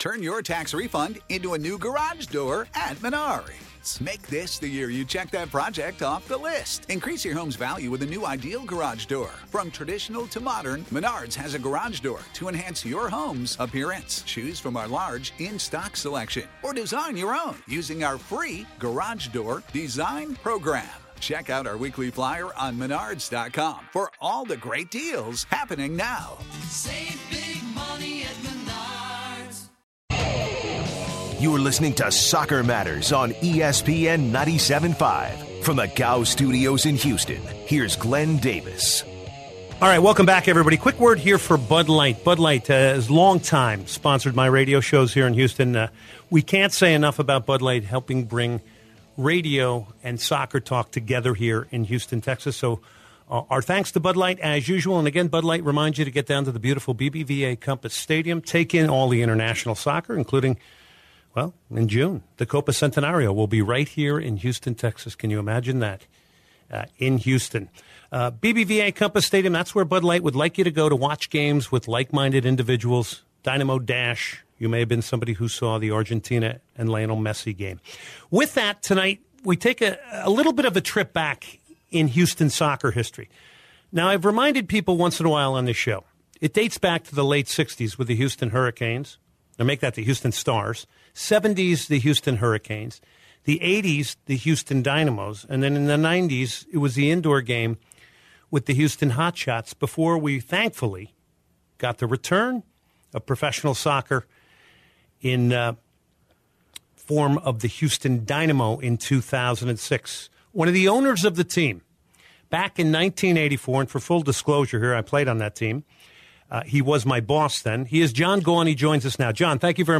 Finally, to close the show, NBC analyst Robbie Earle joins the show to discuss: Jamie Vardy, Alexis Sanchez’s injury, the Portland Timber’s progression, plus much more!